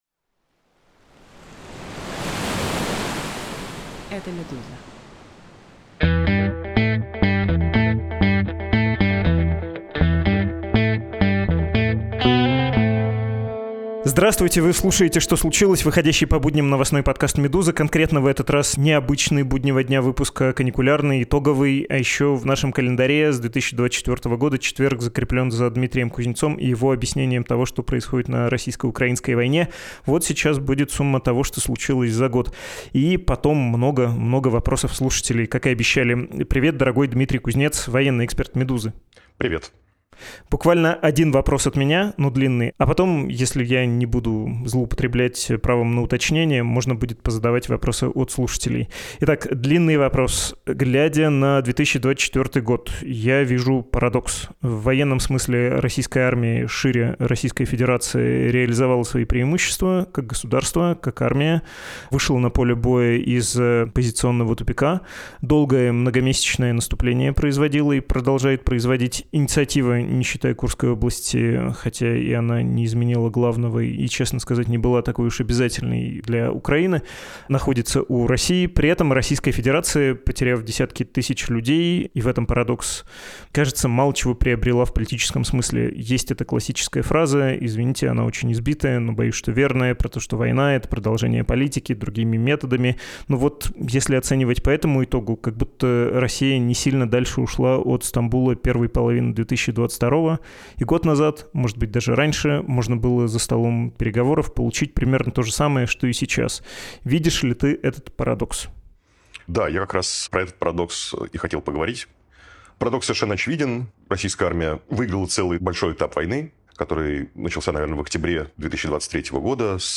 «Что случилось» — новостной подкаст «Медузы».